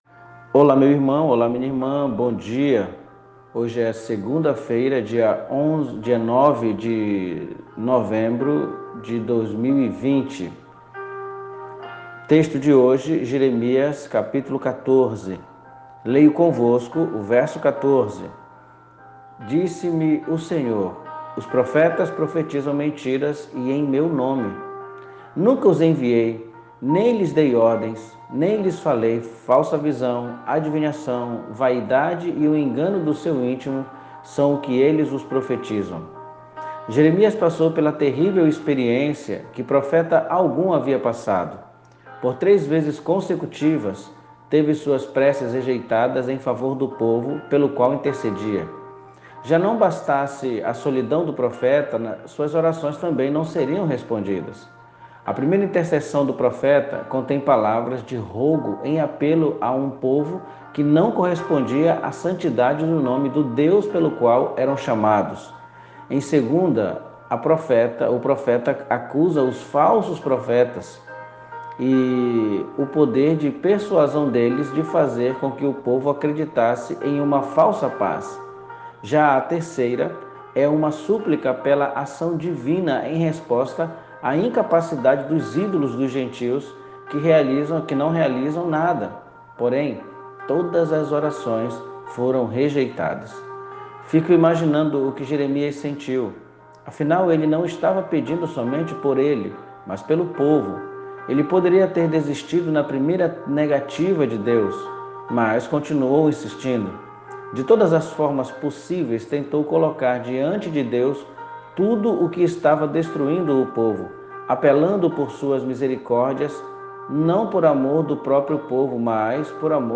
MEDITAÇÃO BÍBLICA